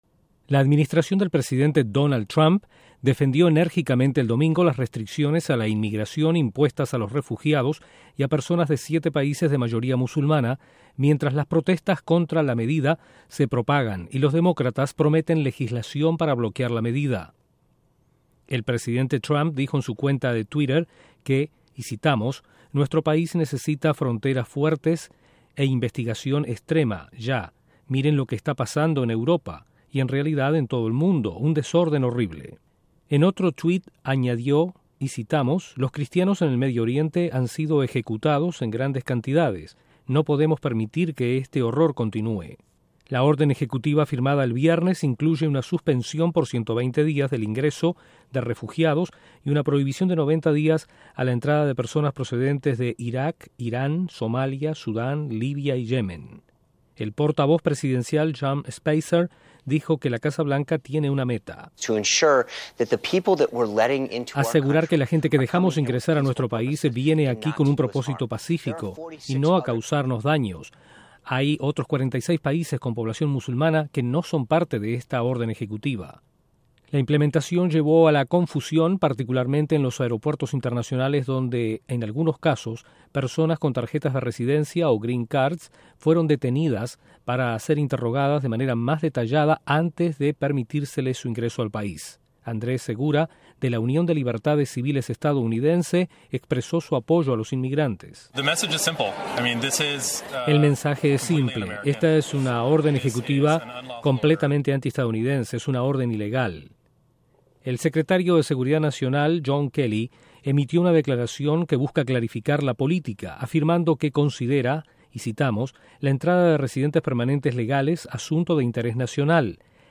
El gobierno del presidente Donald Trump defiende la prohibición al ingreso a EE.UU. de inmigrantes musulmanes y refugiados. Desde la Voz de América en Washington